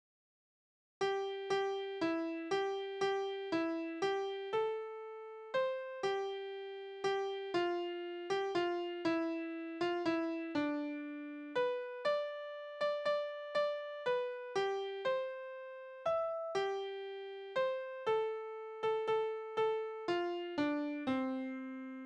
Balladen: Vorspuk
Tonart: C-Dur
Taktart: 3/4
Tonumfang: große Dezime
Besetzung: vokal